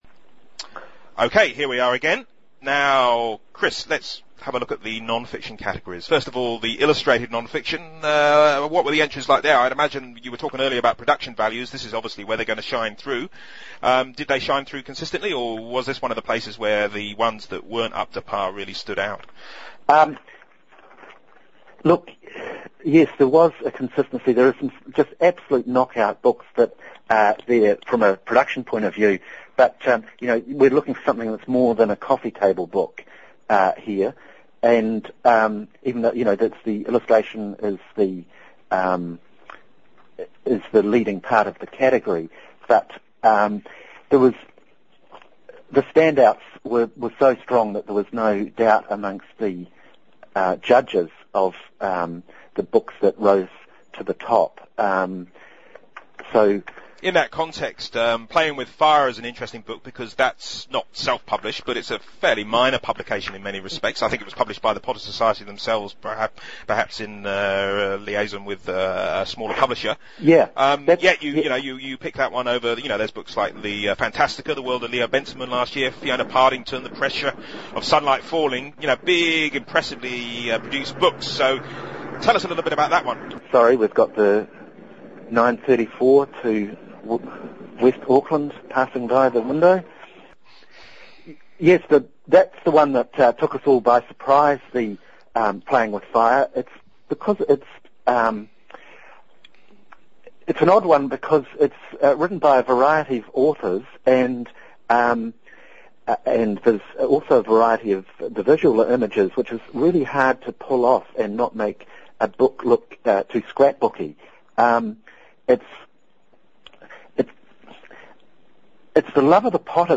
interview part two